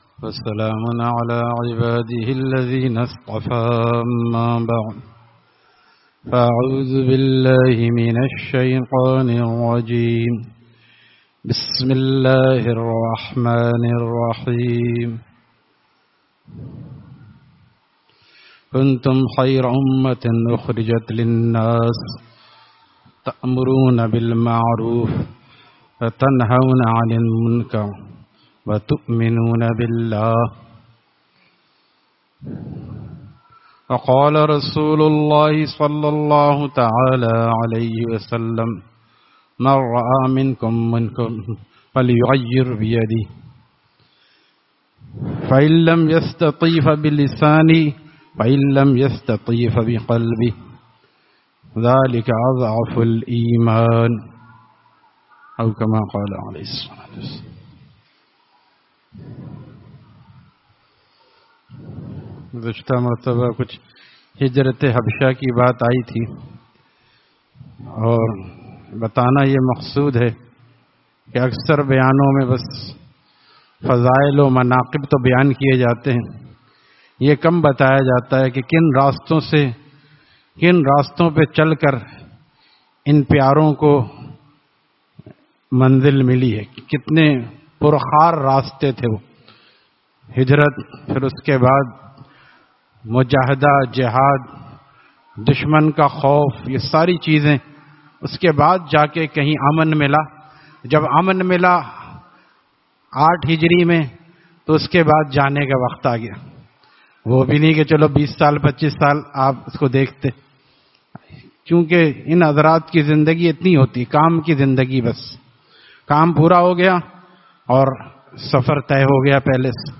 Friday Markazi Bayan at Jama Masjid Gulzar e Muhammadi, Khanqah Gulzar e Akhter, Sec 4D, Surjani Town